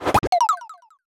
Sound effect of "Coin Box Equip" in Super Mario 3D Land